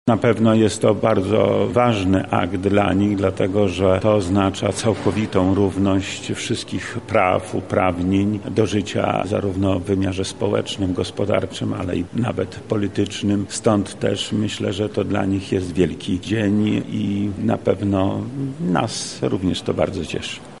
• mówi wojewoda lubelski Lech Sprawka.